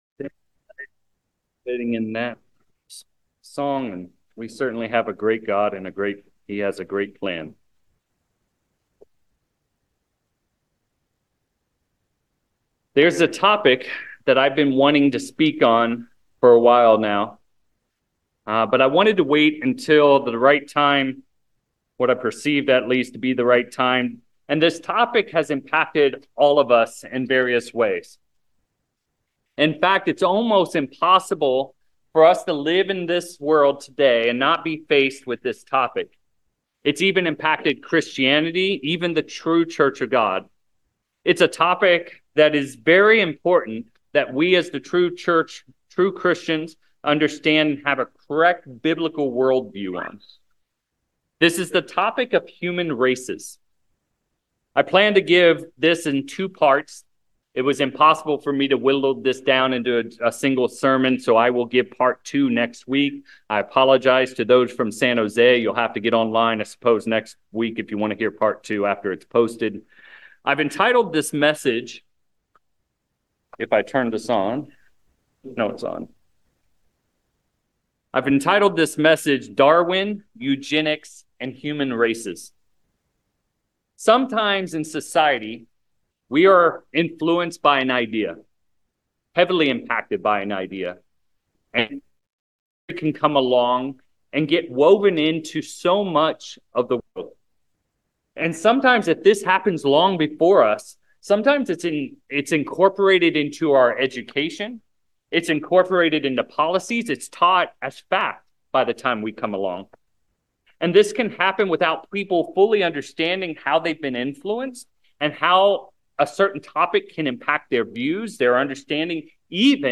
11/2/24 In this sermon, we examine the impact that Darwin’s theory of Evolution has had on the world and more specifically on man’s view of the races of man. What ideas were formed out of man’s rejection of God as their creator?